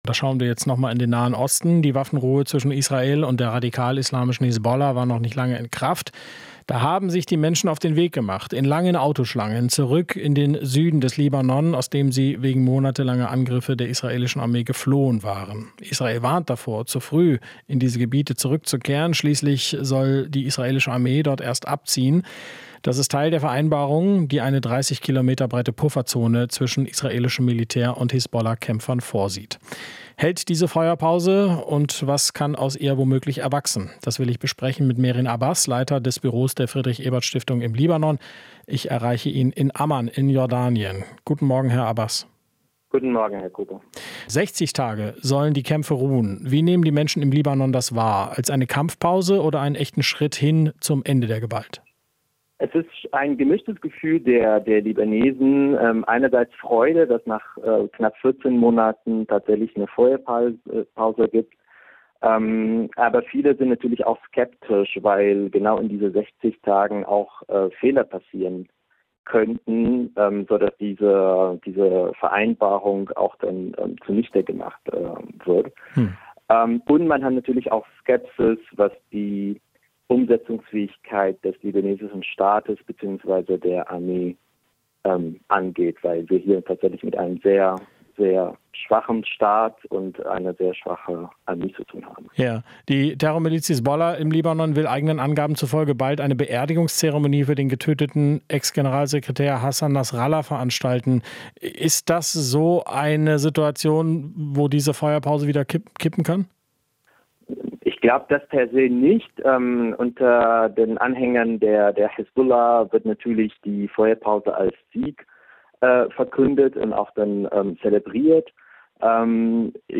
Interview - Waffenruhe im Libanon: Zwischen Freude und Skepsis